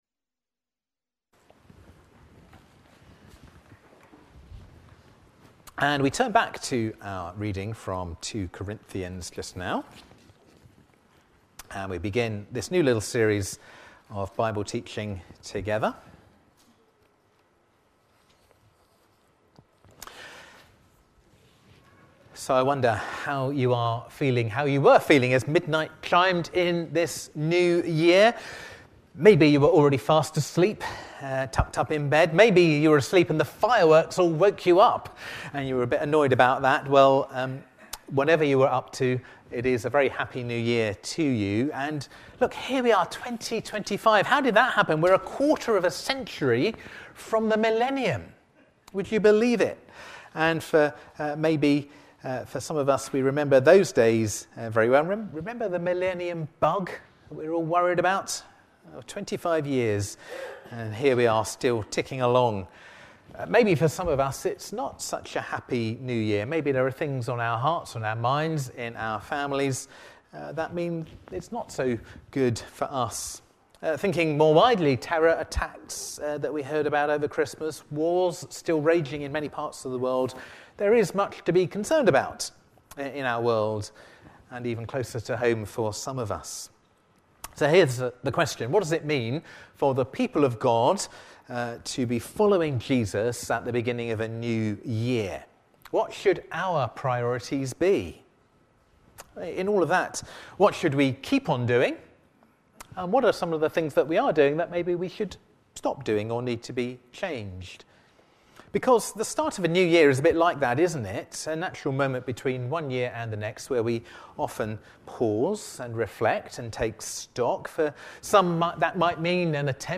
Sermon-on-5th-January-2025.mp3